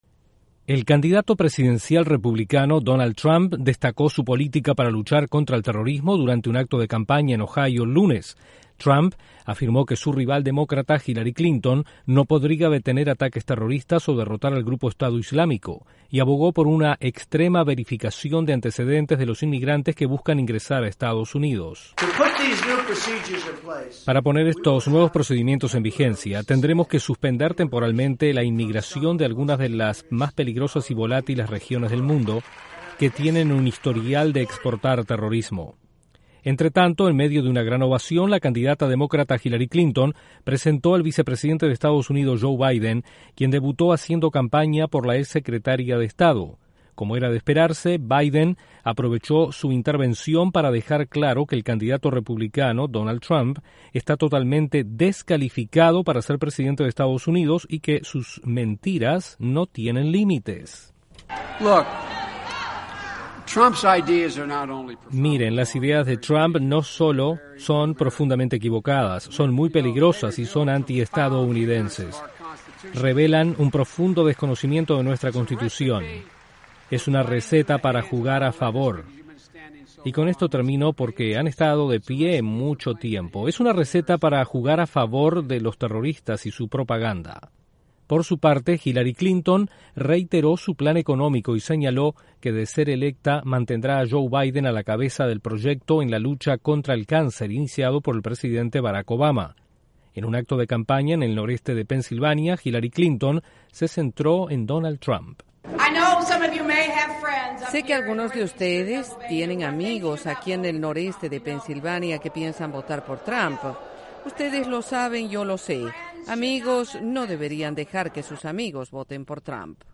Donald Trump presenta plan contra el terrorismo y Hillary Clinton critica a su rival republicano. Desde la Voz de América en Washington informa